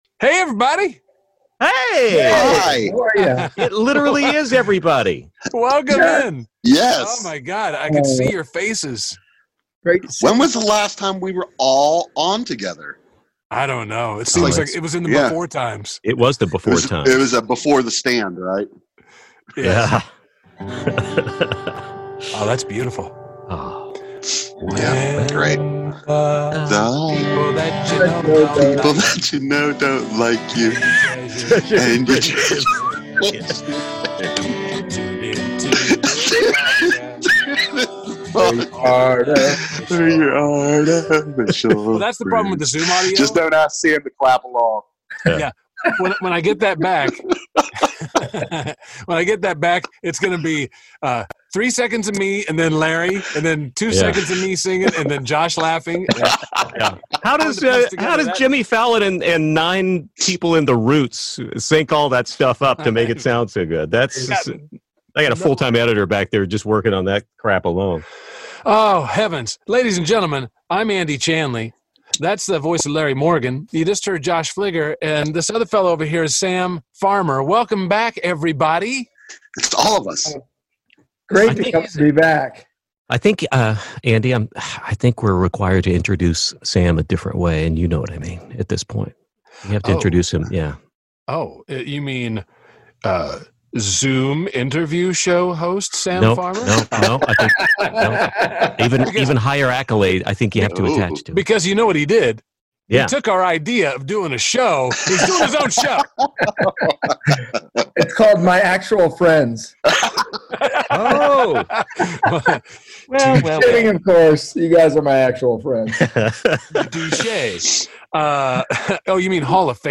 It's a reunion... of sorts, with all four YAFfers joining in remotely from wherever.
Plus your YAF calls at O-AMOK-PM-HAT.